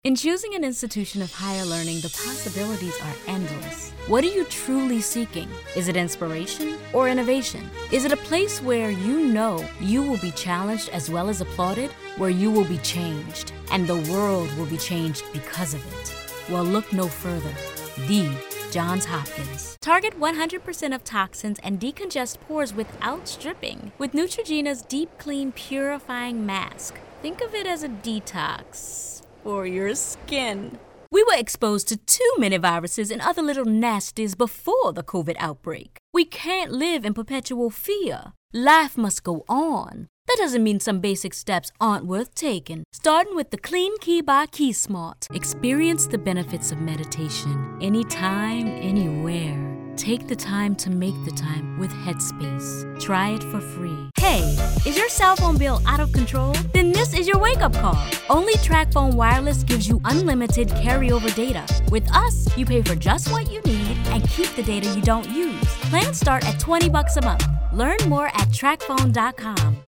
Demo Reel
Commercial Voiceover Demo Reel